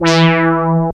72.06 BASS.wav